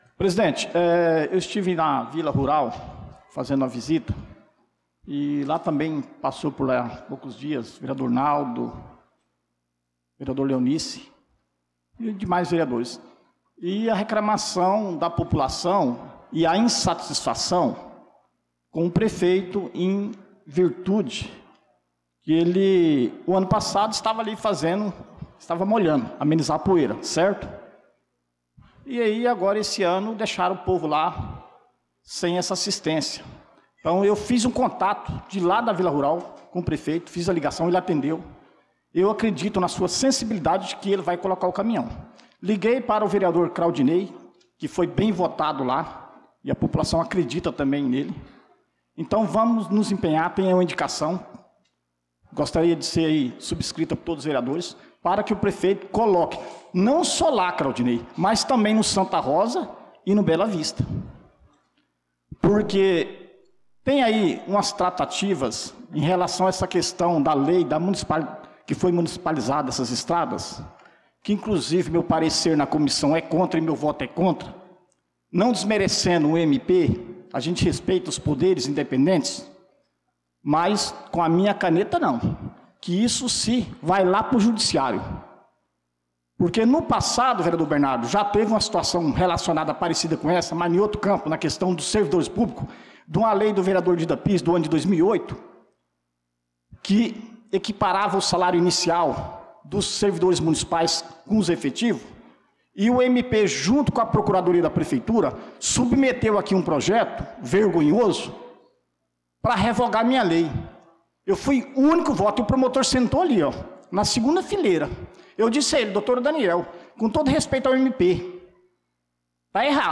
Pronunciamento do vereador Dida Pires na Sessão Ordinária do dia 16/06/2025.